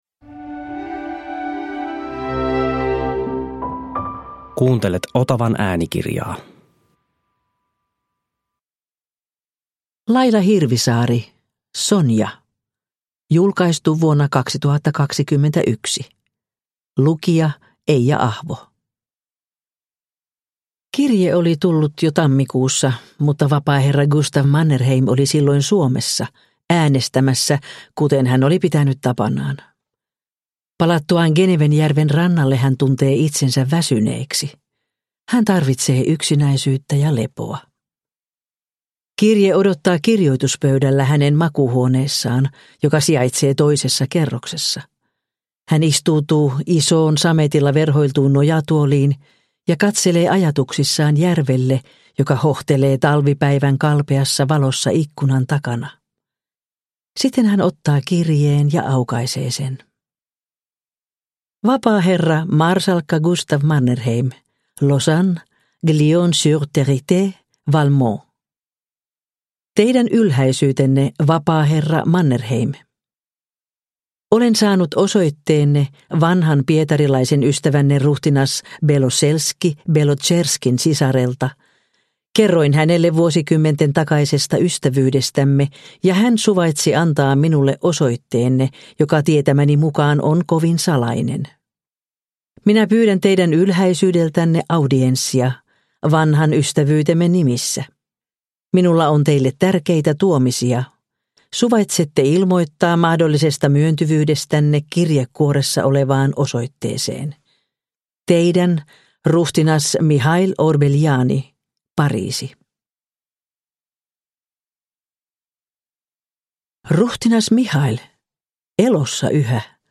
Sonja – Ljudbok – Laddas ner